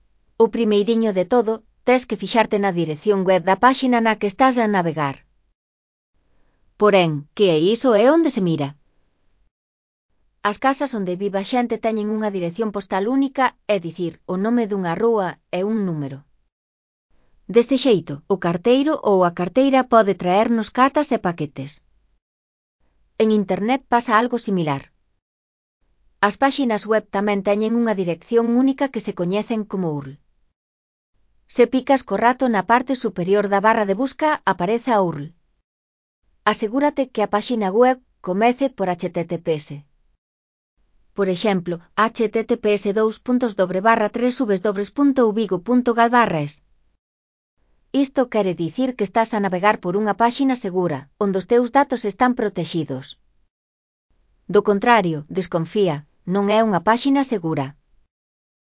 Elaboración propia (proxecto cREAgal) con apoio de IA, voz sintética xerada co modelo Celtia. Web segura (CC BY-NC-SA 4.0)